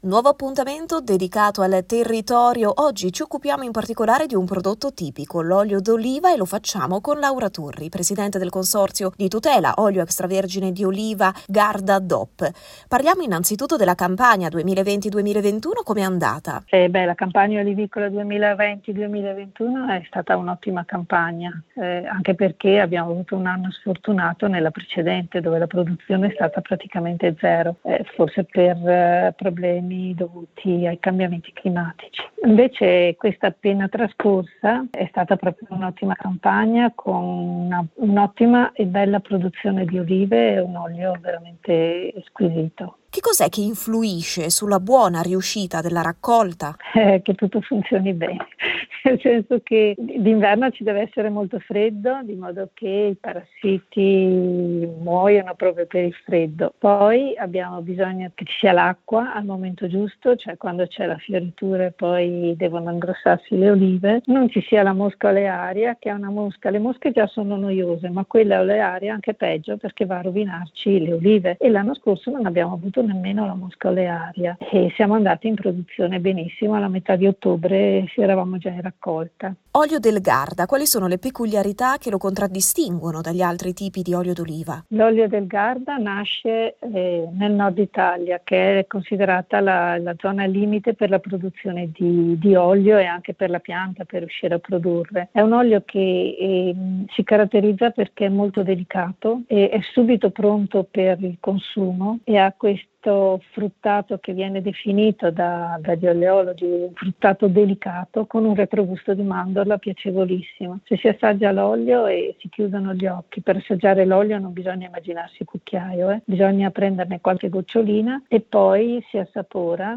Una chiacchierata sulle peculiarità di questo prodotto, sull’andamento del settore in questo anno così particolare e su cosa quali siano i fatto che influiscono sulla buona riuscita di una campagna olivicola: